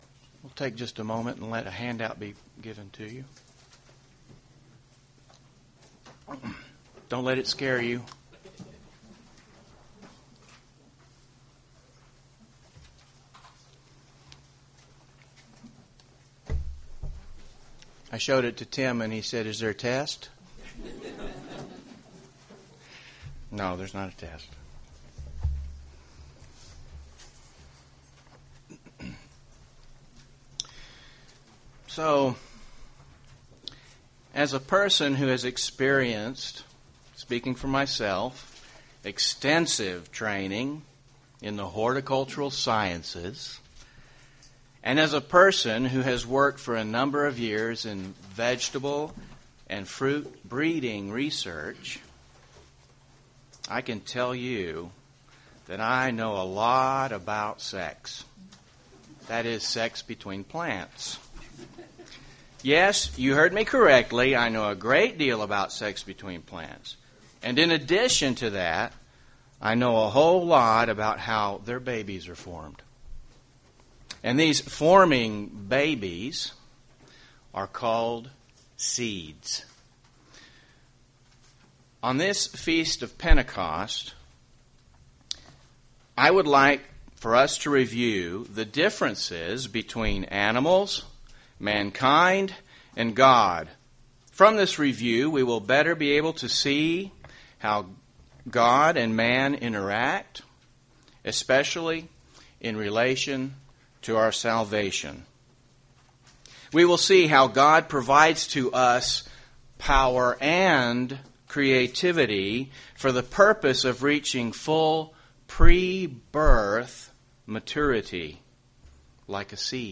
On this Feast of Pentecost, I would like us to review the differences between animals, mankind, and God.